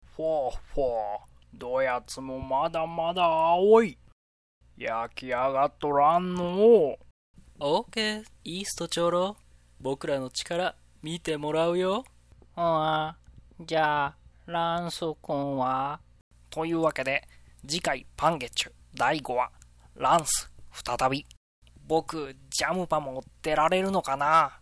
あっ、そうそうバックに音楽入れたらもっと立派になるんですが（ハッタリ効くし、最近はこれで“凝っている”と見せかけられる）『声で遊ぶサイト』である以上、なるべく声だけで行きたい。